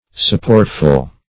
Supportful \Sup*port"ful\, a. Abounding with support.